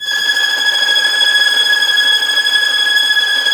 Index of /90_sSampleCDs/Roland - String Master Series/STR_Vlns Tremelo/STR_Vls Trem wh%